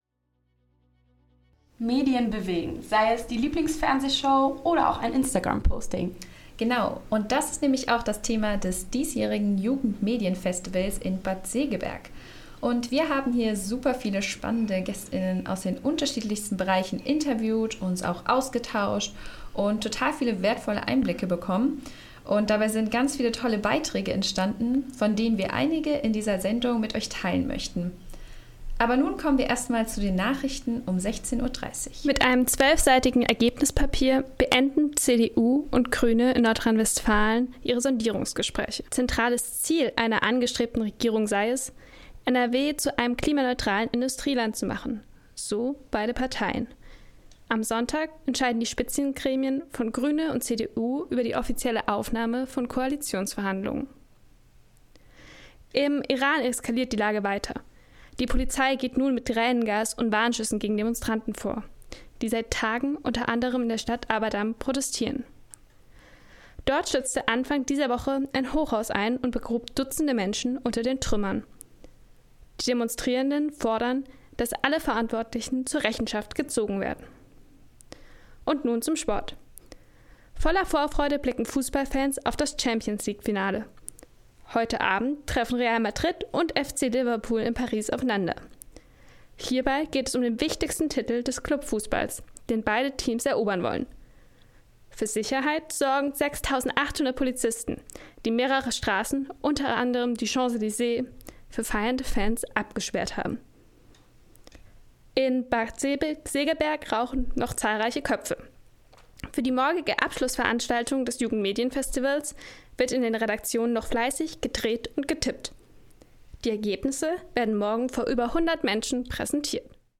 Anmoderation und 16 Uhr Nachrichten